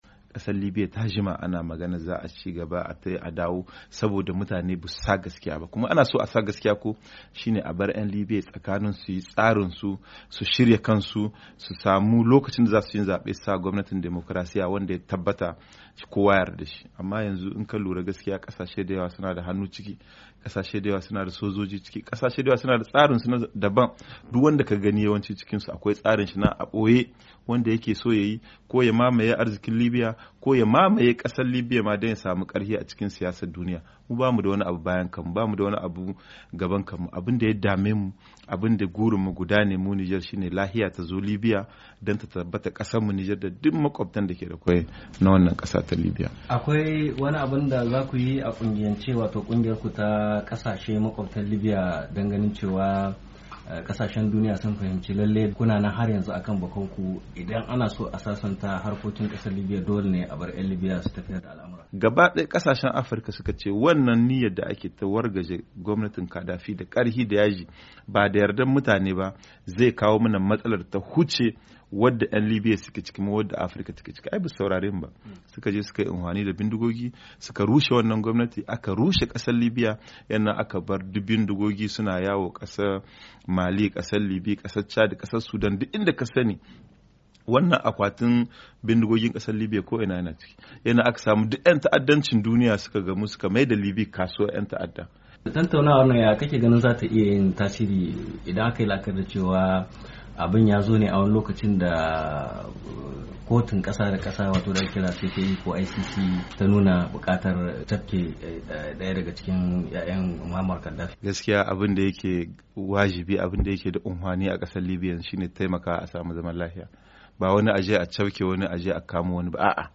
Ministan harakokin wajen Nijar Ibrahim Yabuba ne ya bayana haka.a hirarsa